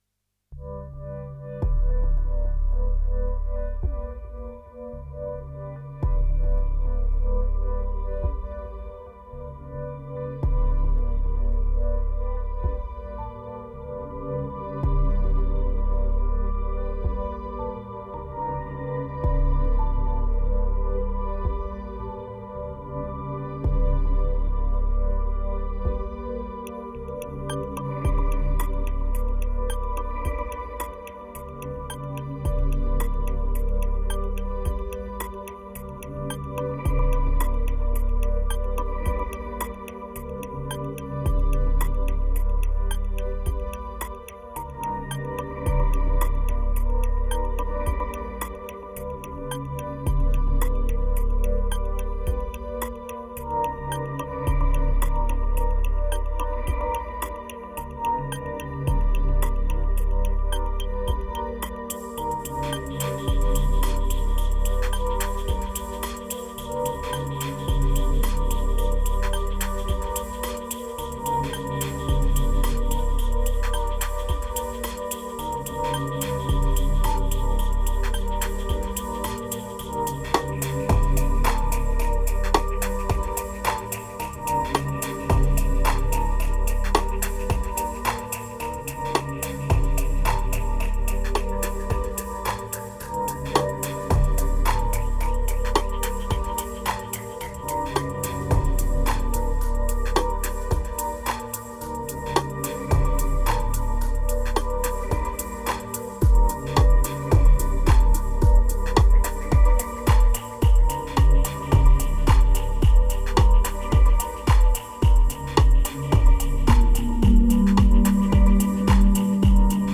2062📈 - 5%🤔 - 109BPM🔊 - 2014-05-20📅 - -266🌟